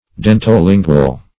Dentolingual \Den`to*lin"gual\, a.